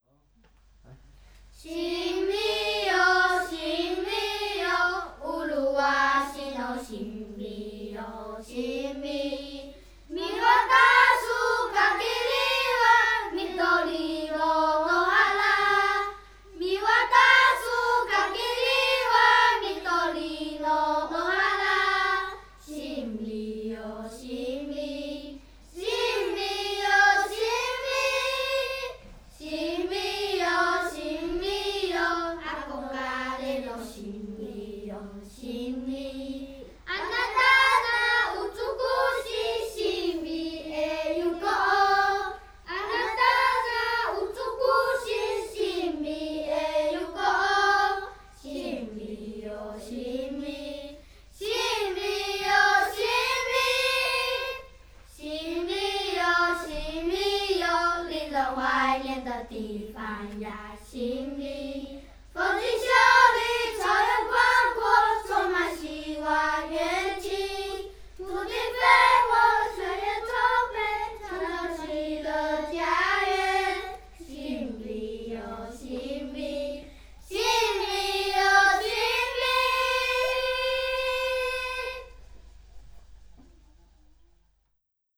„sinbiyo sinbi“ – Lied von Uyongu Yatauyungana (高一生). Es ist ein Lied, das die Stammesangehörigen ermutigte, niederzulassen und in der Region Sinbi （新美） zu siedeln und dort ein neues Leben aufzubauen.